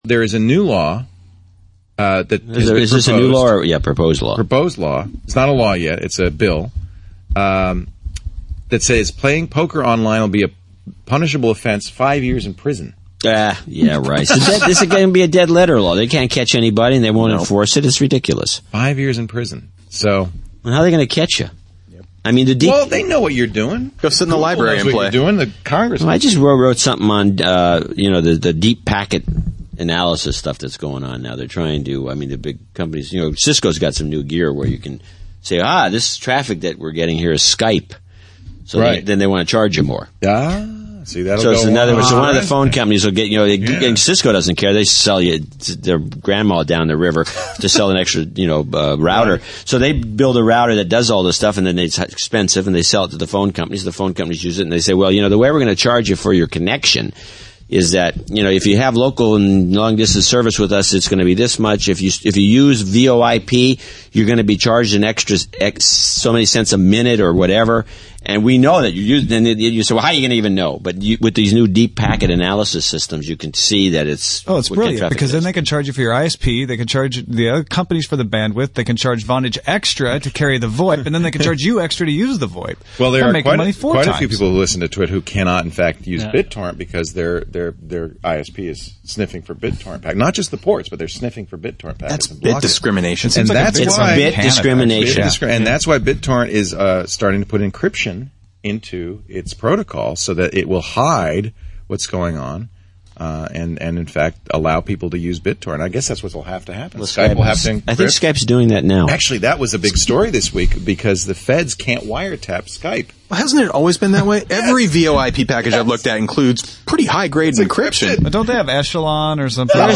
It is a roundtable discussion of the possibility of routers being programmed to look inside packets to detect illegal gambling or other services. This violation of the end-to-end principle would allow ISPs to bill for certain services like telephony (VOIP).